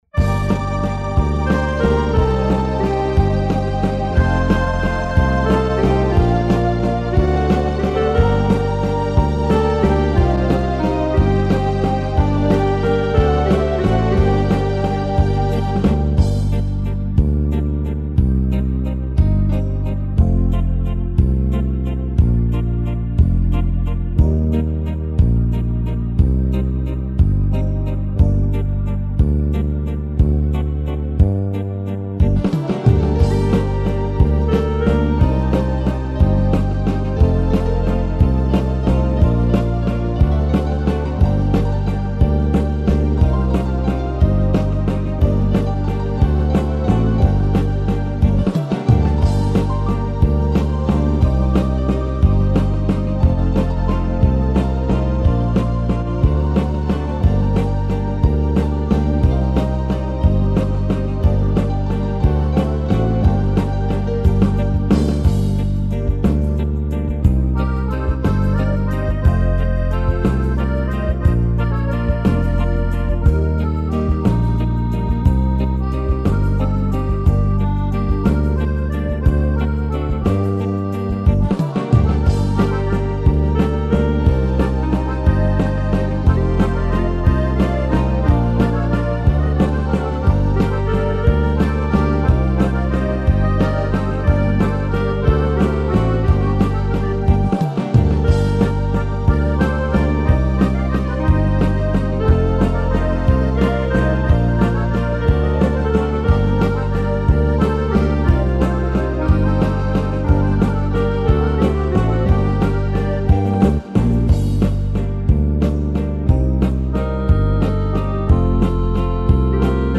"Малая Родина" песня, которую я написала на слова нашей поэтессы Н.И.Гаранжи. Распространила на районном методобединении учителей музыки, фонограмма получилась удачной, песню запели все школы района.